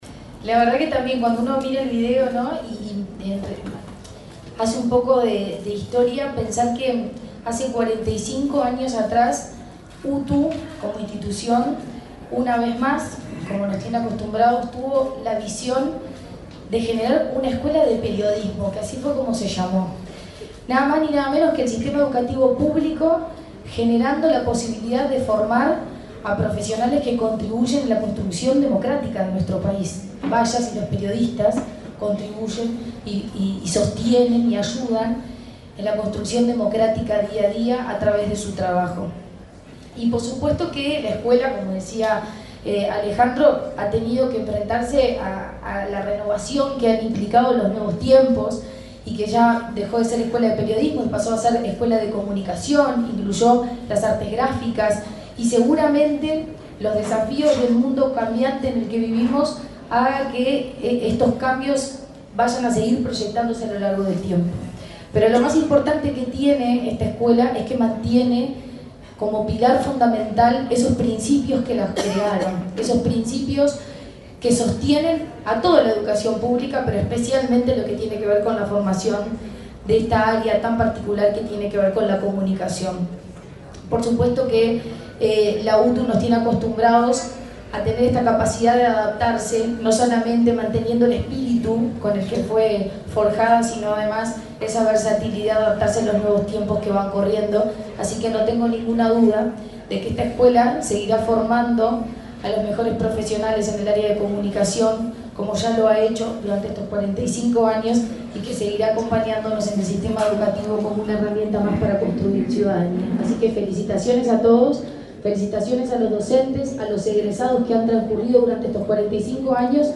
Palabras de la presidenta de ANEP, Virginia Cáceres
La presidenta de la Administración Nacional de Educación Pública (ANEP), Virginia Cáceres, participó, el lunes 29 en Montevideo, en la celebración de